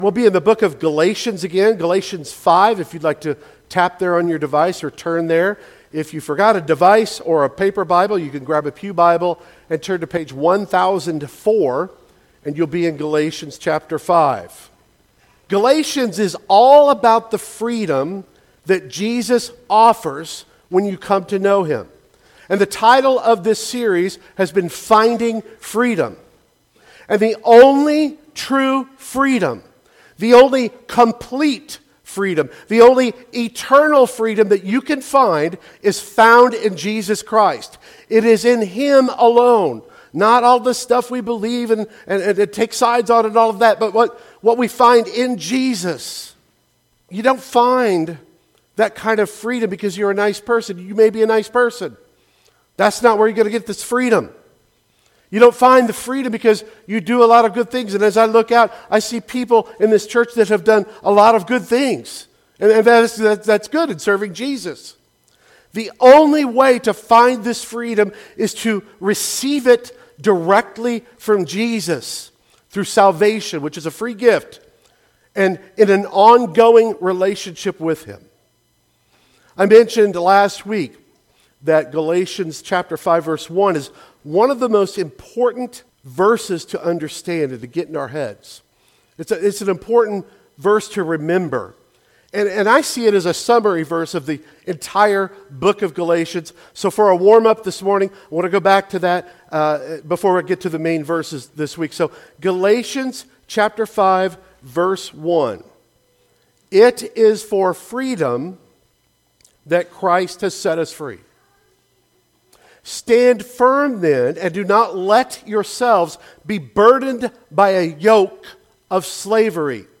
Finding Freedom Passage: Galatians 5:7-12 Service Type: Sunday Worship Service « Christ Has Set Us Free